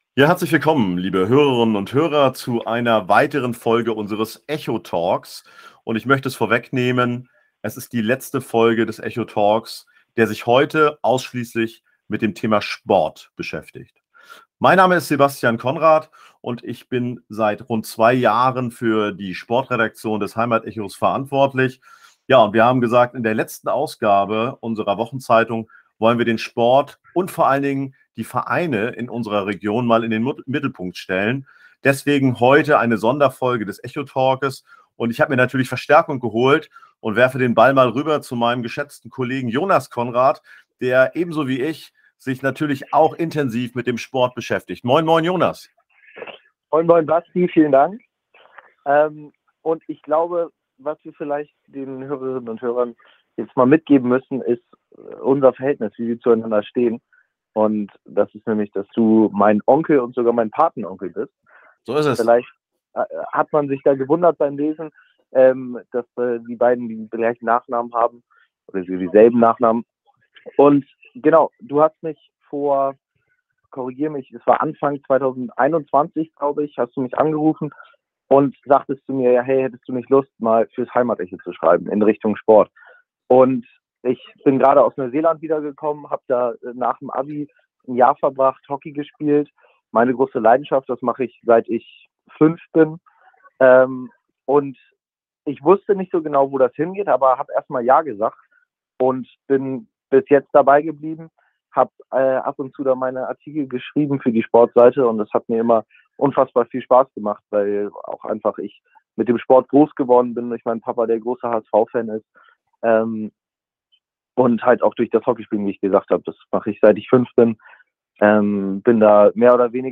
HAMBURG Ein letztes Mal trafen sich die Sportredakteure des Heimat-Echo zu ihrer wöchentlichen Redaktionskonferenz. Im Echo-Talk blicken sie zurück auf bewegende Ereignisse, neue Angebote und interessante Erfahrungen. Gemeinsam ziehen die sportbegeisterten Journalisten ein Fazit, welches Sportvereinen und Aktiven Mut machen sollte.